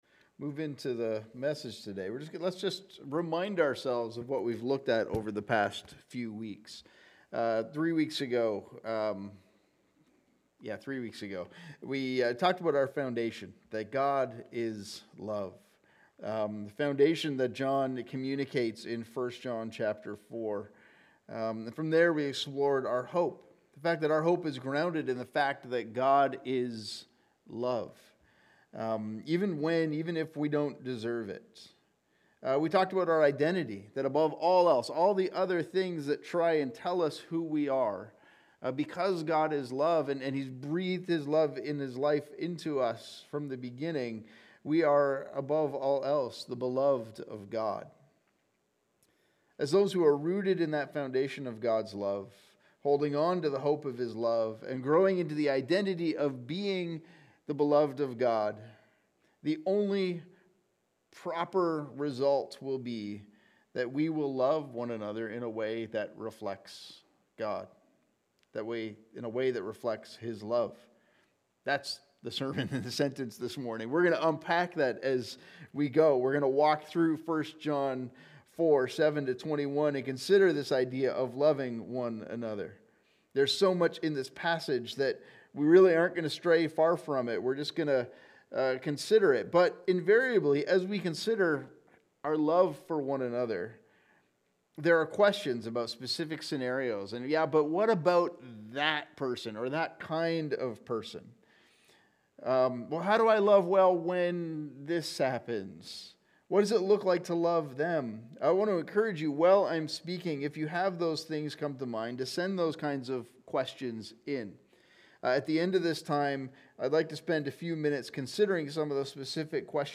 Sermons | Covenant Christian Community Church